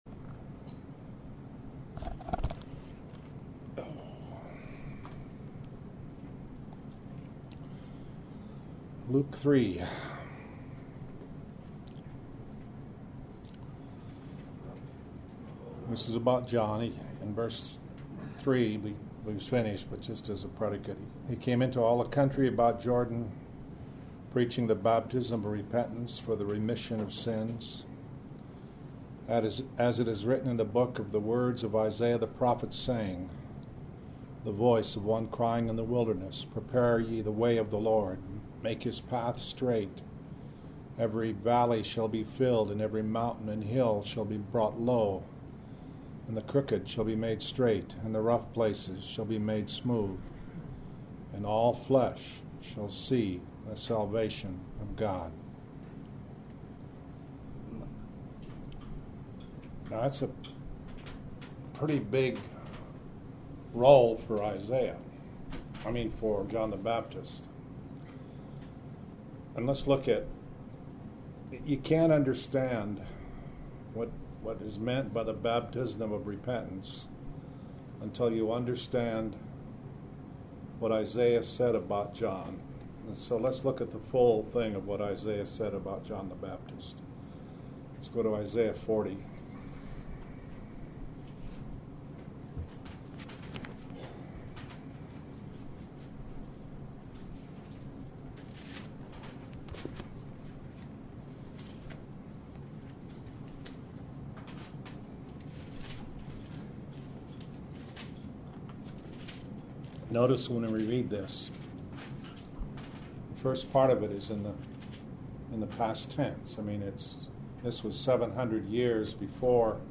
Morning Bible Studies